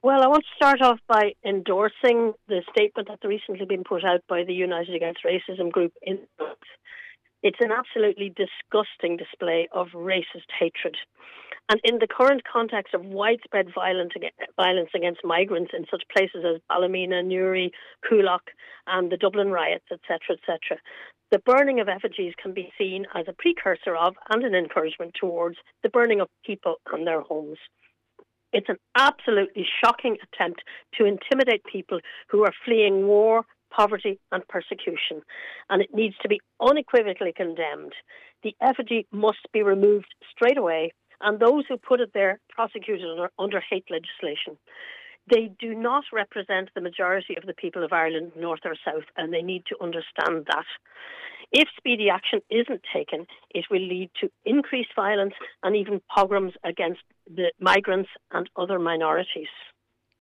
a member of United Against Racism Donegal is supporting calls for the effigies to be removed: